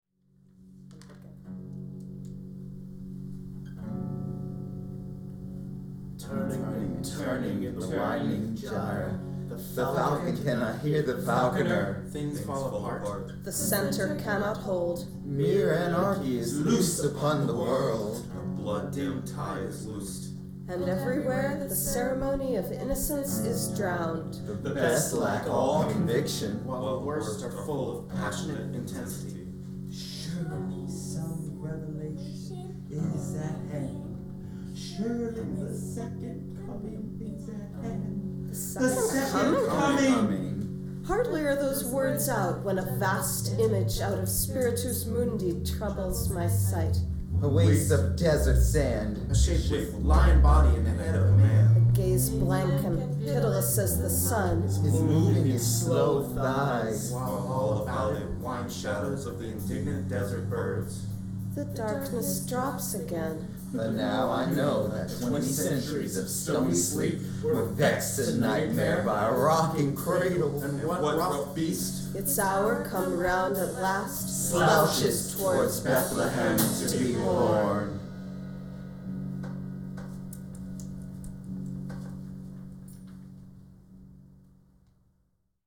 ” and the last chorus we recorded in 2010. https